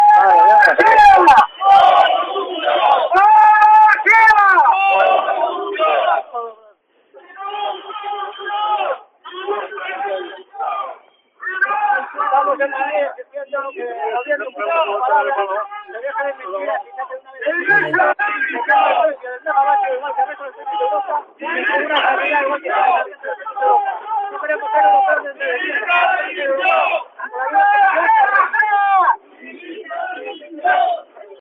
Griterío en la concentración de trabajadores de Alcoa en Madrid